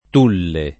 vai all'elenco alfabetico delle voci ingrandisci il carattere 100% rimpicciolisci il carattere stampa invia tramite posta elettronica codividi su Facebook Tulle [fr. tül ] top. (Fr.) — con t‑ minusc. come s. m., per lo più con pn. italianizz. [ t 2 lle ]